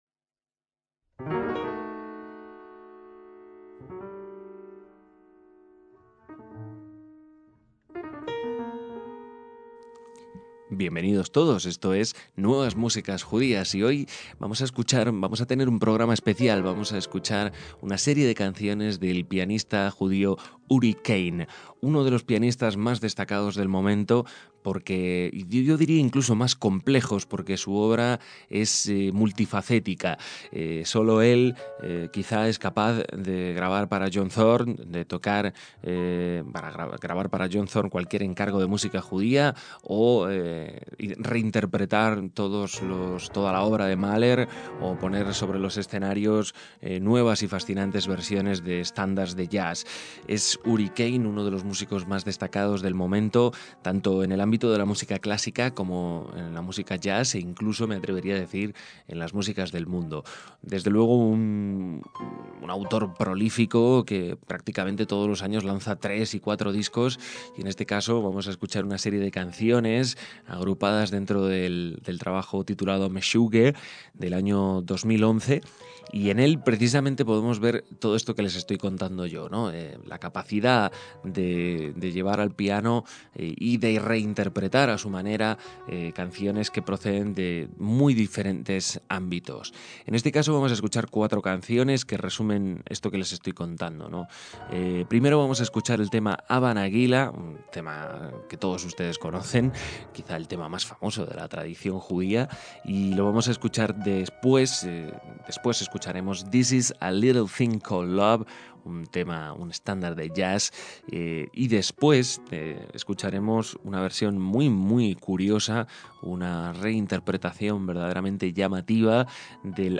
pianista
grabado en directo en un concierto en Roma